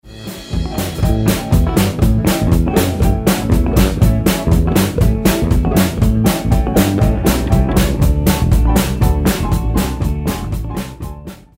10-Bar Rockabilly Blues.